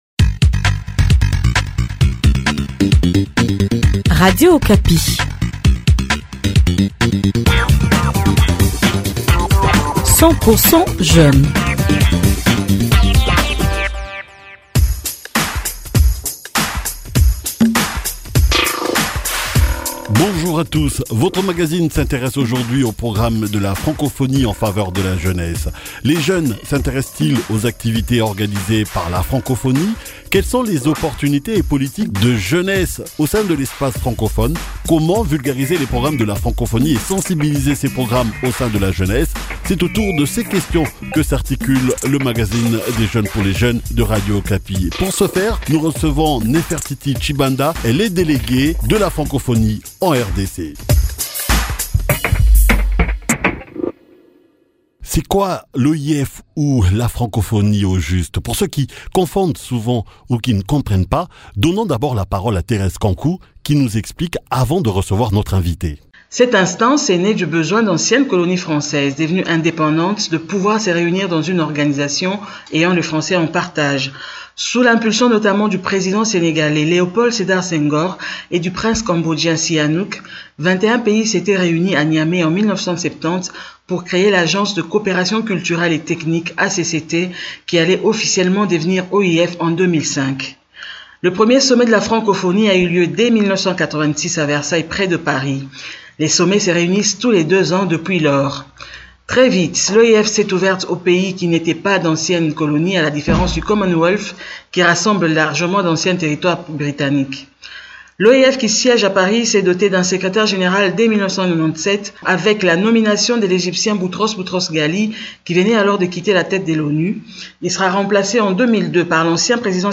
Pour en parler, nous recevons Néfertiti Tshibanda, Déléguée Générale à la Francophonie en RDC.